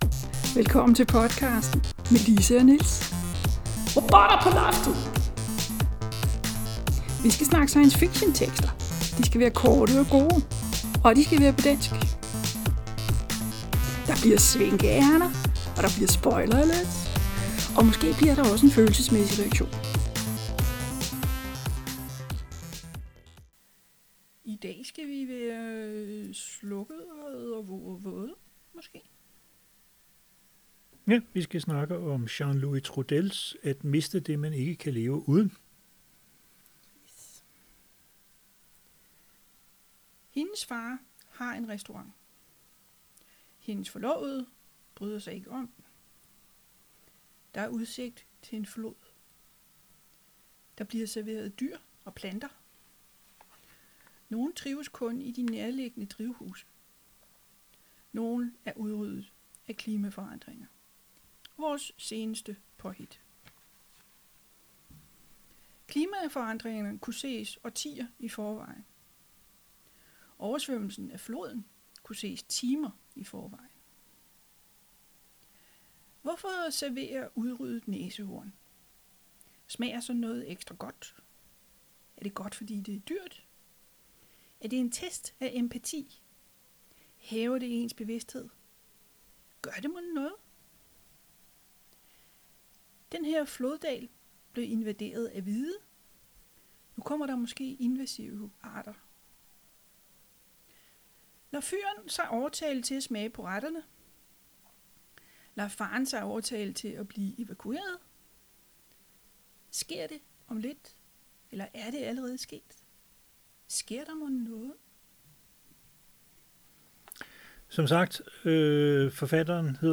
for langt fra mikrofonen, hænder for tæt på.